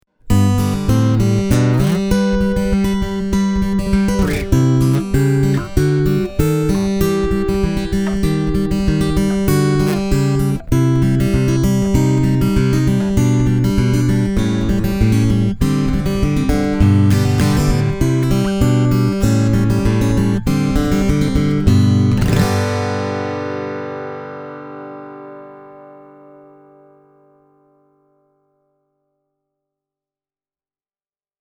TW1000HSRE:n soundissa on iso ja lämmin basso, hyvin moniulotteinen keskialue, sekä kimaltelevaa diskanttia.
Fishmanin välittämä signaali ei ole kuiva tai nasaalinen, vaan vain hiukan suorasukkaisempi, ja aavistuksen verran täyteläisempi versio tämän Tanglewoodin akustisesta soinnista.
Fishman – näppäily
fishman-e28093-fingerstyle.mp3